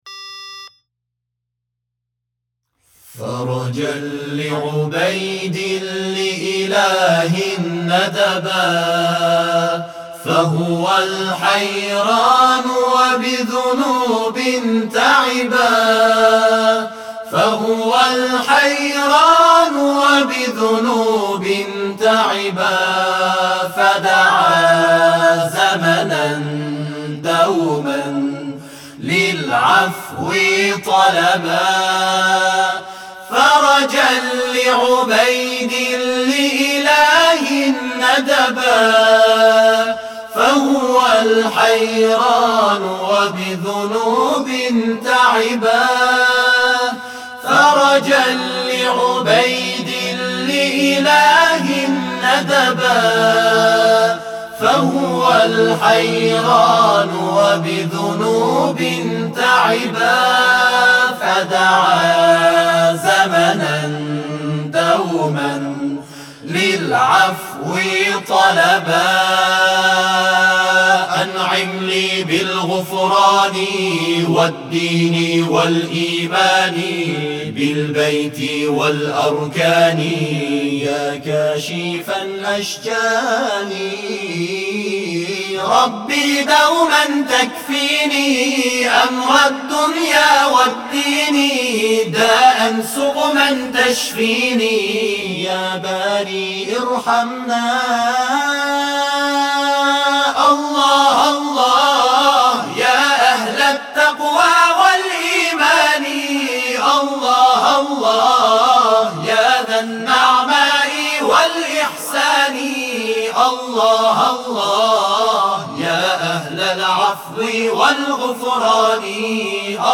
این گروه‌ها در روزهای ۱۷ تا ۲۲ آبان‌ماه اجرای خود در مرحله نهایی را به صورت ضبط استودیویی انجام دادند.
برچسب ها: مسابقات قرآن ، چهل و سومین دوره ، تواشیح ، همخوانی قرآن